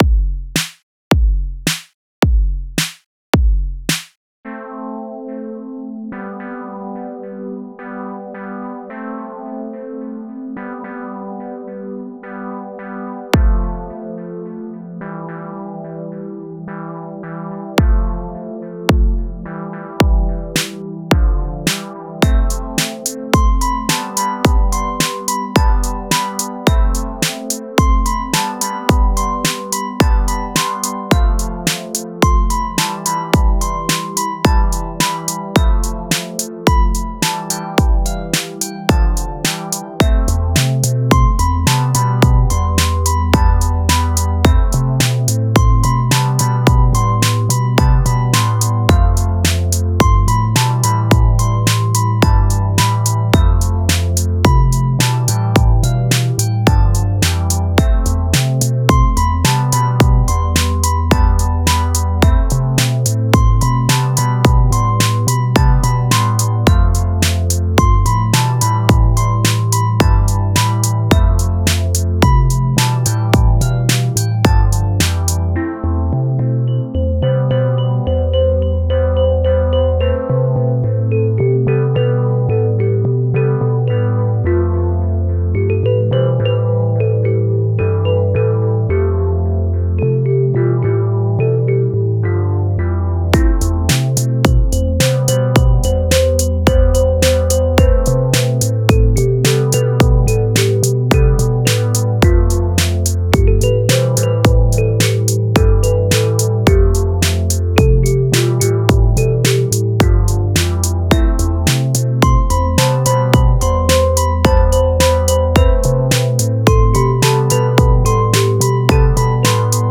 Here’s the same beat made with Force/MPC built-in synths: